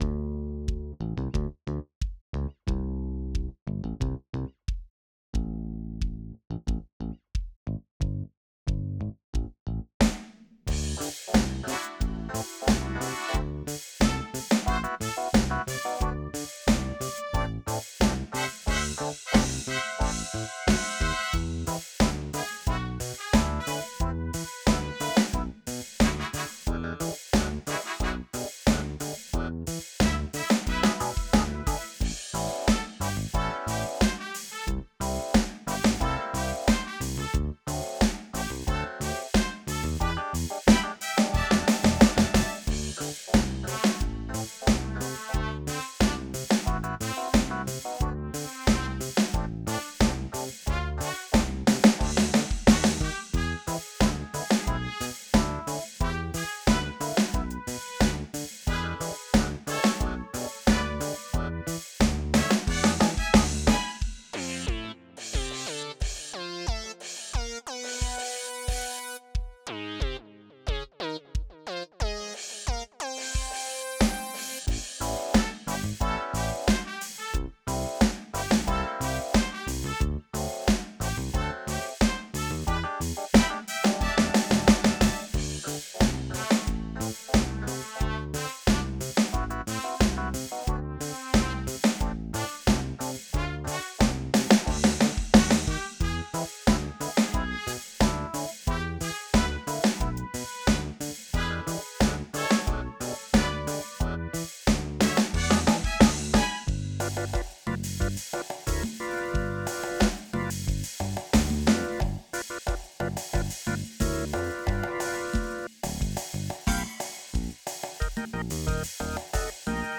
90bpm